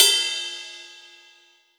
RIDE_CS.WAV